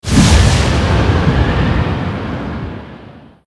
warrior_skill_cycloneslash_04_fire.ogg